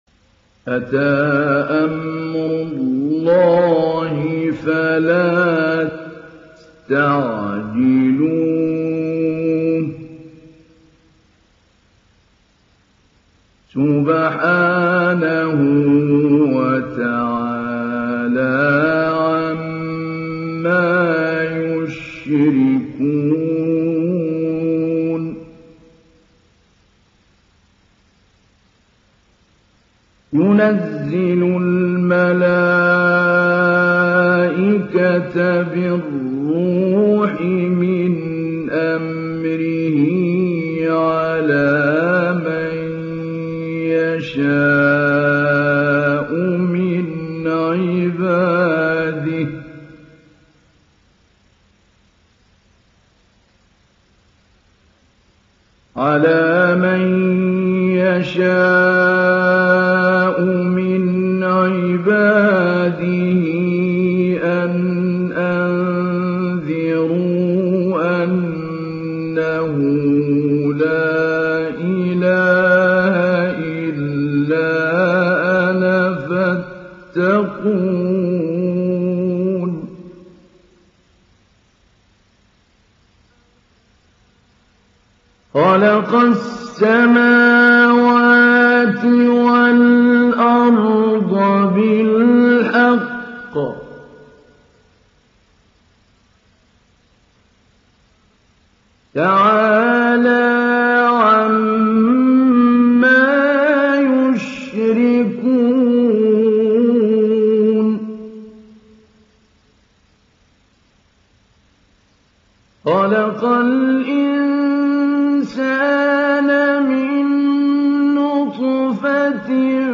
সূরা আন-নাহ্‌ল ডাউনলোড mp3 Mahmoud Ali Albanna Mujawwad উপন্যাস Hafs থেকে Asim, ডাউনলোড করুন এবং কুরআন শুনুন mp3 সম্পূর্ণ সরাসরি লিঙ্ক
ডাউনলোড সূরা আন-নাহ্‌ল Mahmoud Ali Albanna Mujawwad